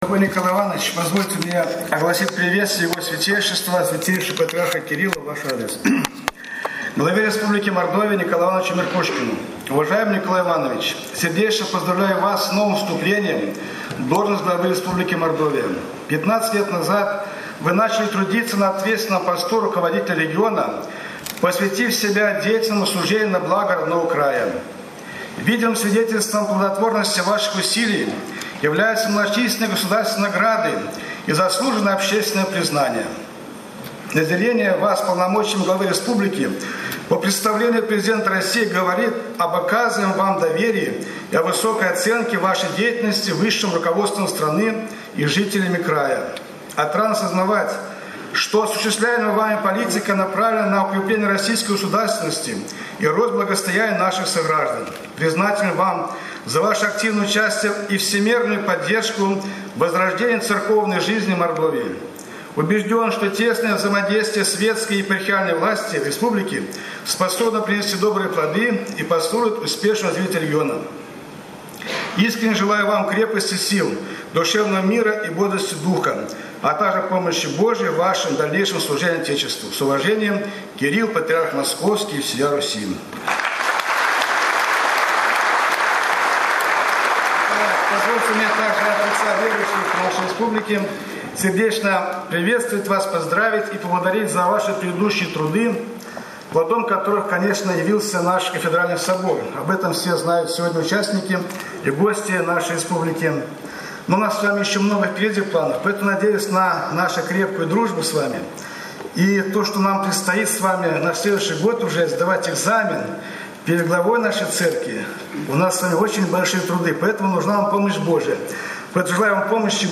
Он зачитал слова поздравления Святейшего Патриарха Московского и Всея Руси КИРИЛЛА.
Слово Владыки Варсонофия на инаугурации